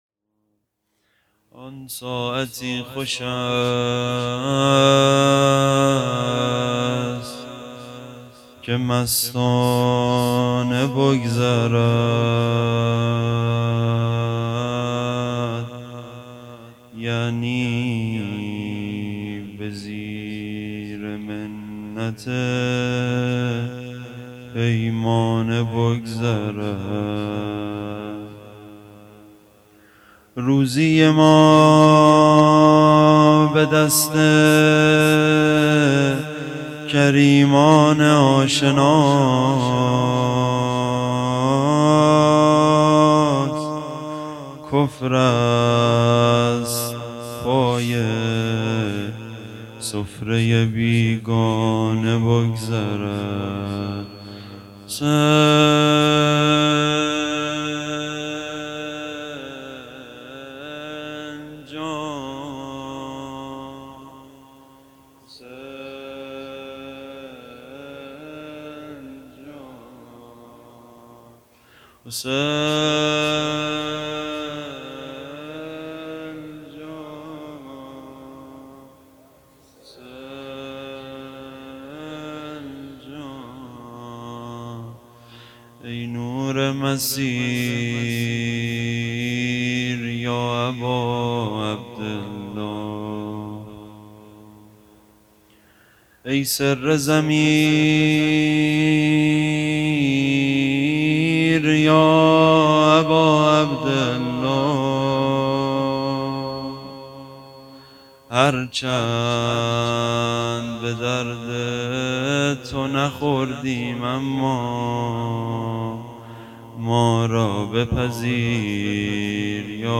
روضه
شب ششم محرم ۱۴۴۴